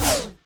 poly_shoot_bone.wav